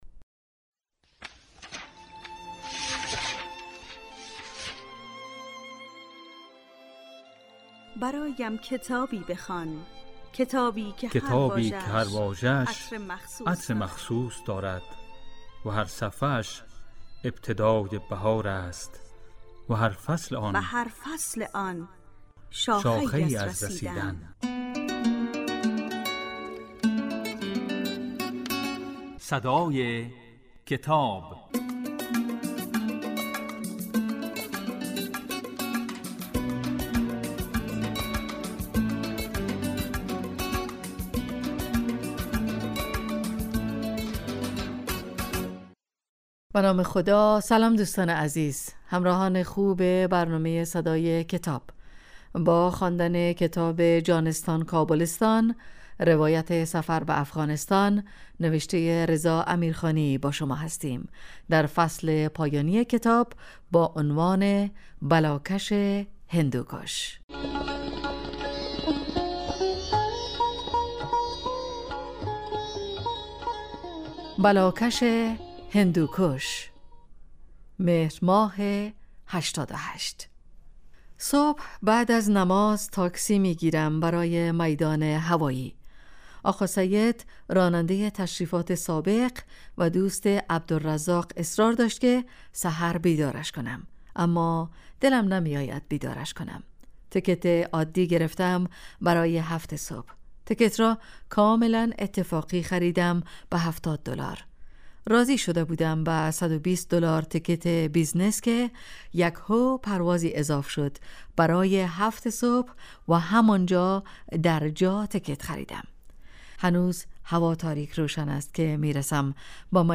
این برنامه کتاب صوتی است و در روزهای یکشنبه، سه شنبه و پنج شنبه در بخش صبحگاهی پخش و در بخش نیمروزی بازپخش می شود.